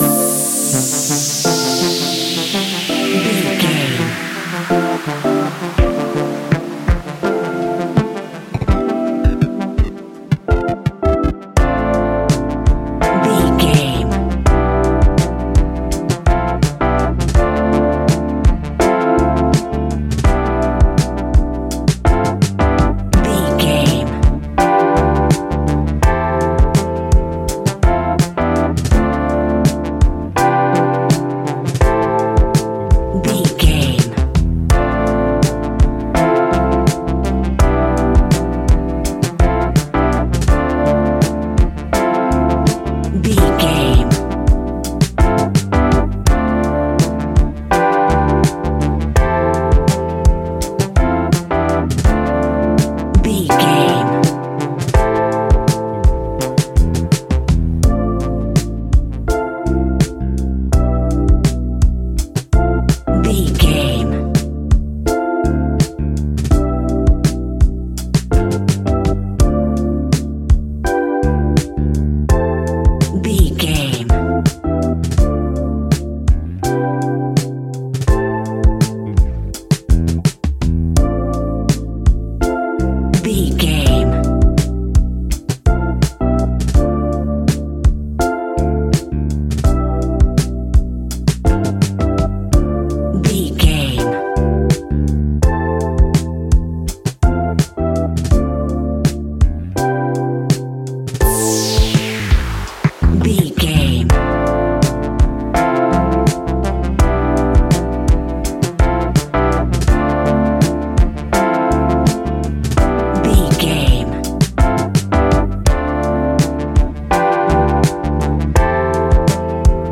Ionian/Major
laid back
Lounge
sparse
new age
chilled electronica
ambient
atmospheric